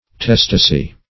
Search Result for " testacy" : The Collaborative International Dictionary of English v.0.48: Testacy \Tes"ta*cy\, n. [See Testate .]